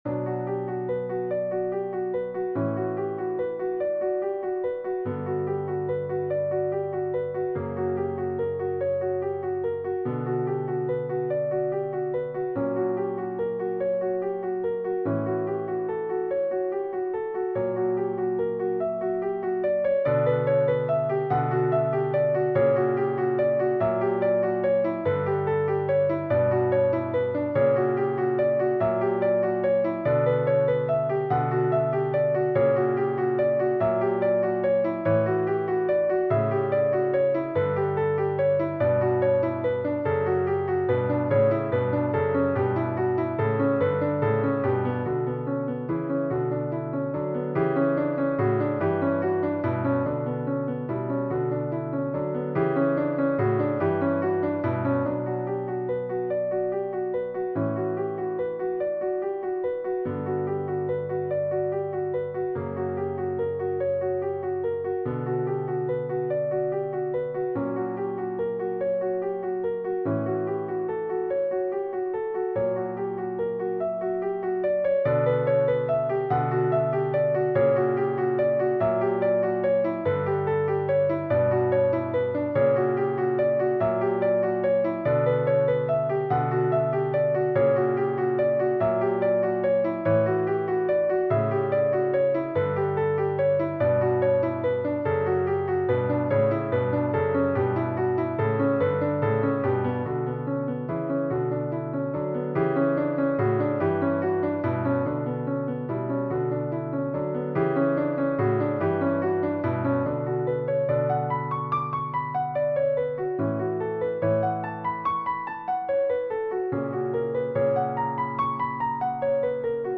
Modern Classical, Romantic Period